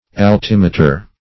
Altimeter \Al*tim"e*ter\, n. [LL. altimeter; altus high +